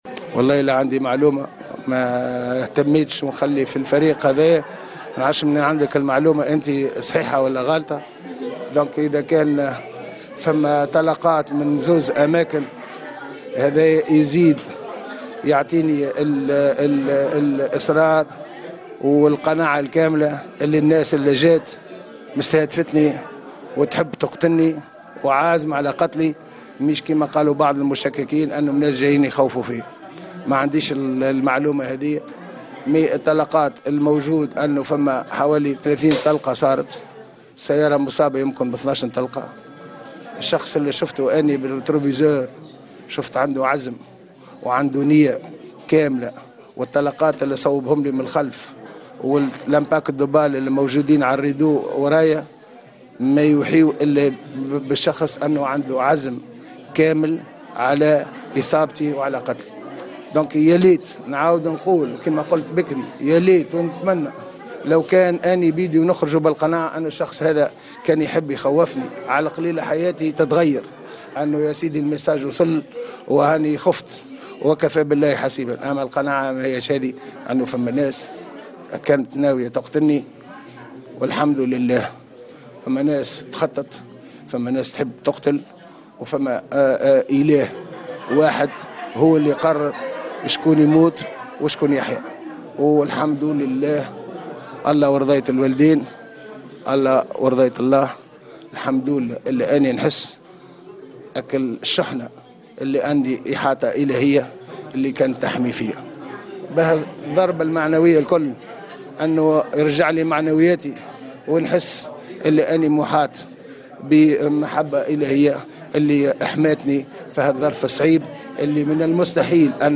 وقال شرف الدين اليوم، السبت في تصريح ل"الجوهرة أف أم" إن تأكد هذه الفرضية لن يزيده إلا قناعة بأن الجهة التي أطلقت عليه النار عازمة على قتله خلافا لما قاله بعض المشككين من أن الهدف هو إخافته فقط، وفق تعبيره.